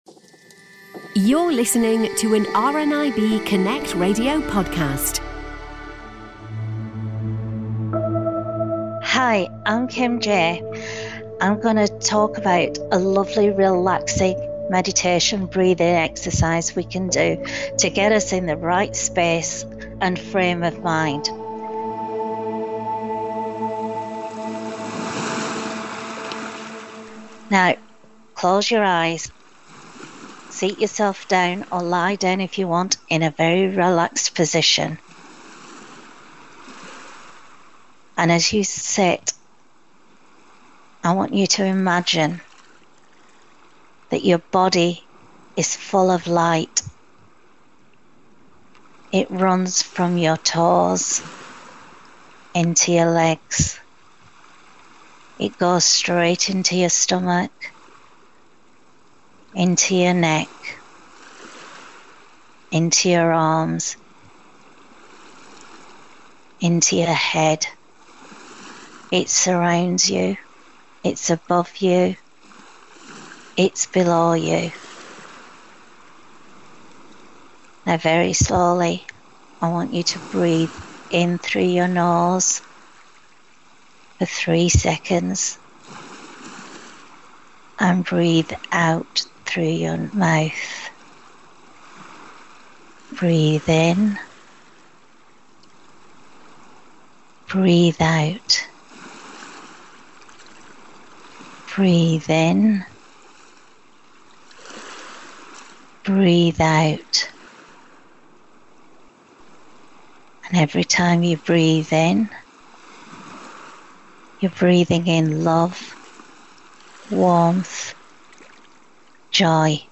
Quick Breathing Exercise To Support Your Wellbeing